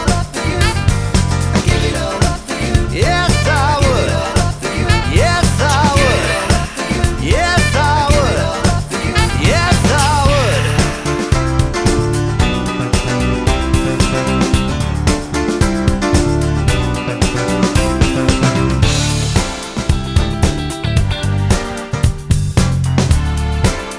Live Pop (1980s)